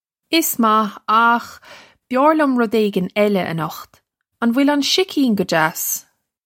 Iss mah, okh byar lyum rud ay-gin ella a-nukht. Un vwill un shih-keen guh jass?
This is an approximate phonetic pronunciation of the phrase.